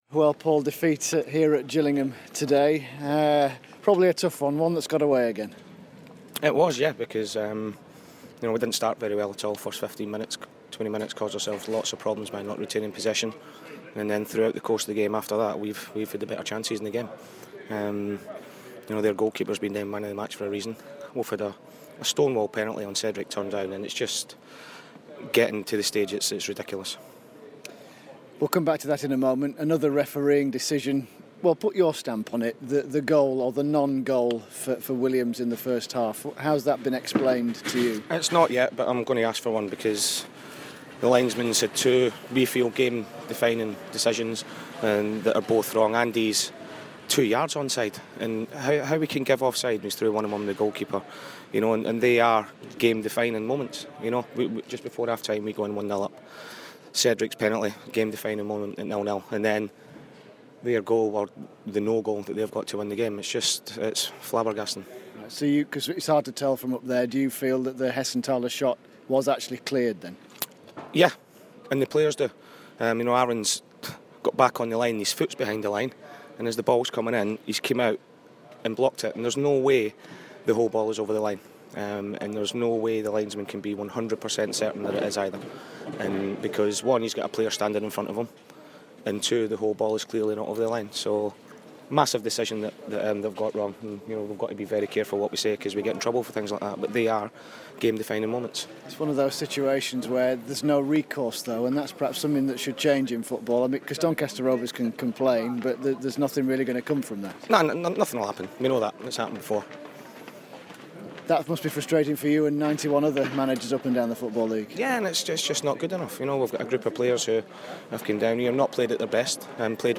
INTERVIEW: Doncaster manager Paul Dickov post Gillingham